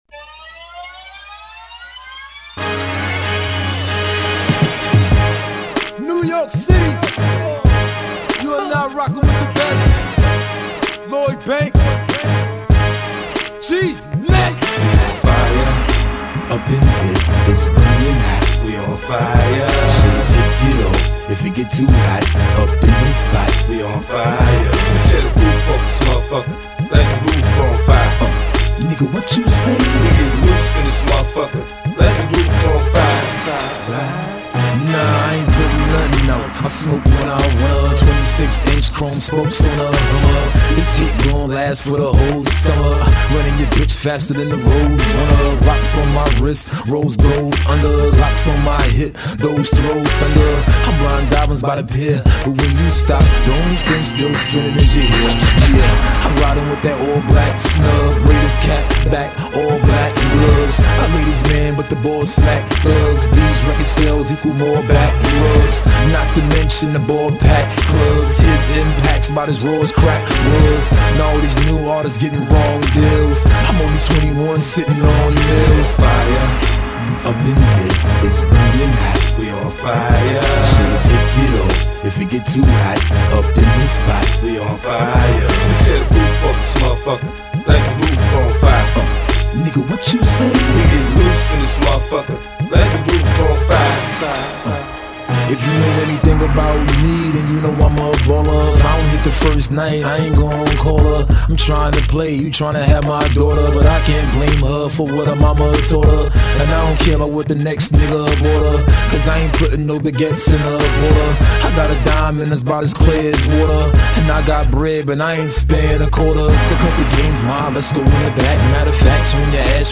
• [해외 / REMIX.]
긴장감을 늦출수없네요..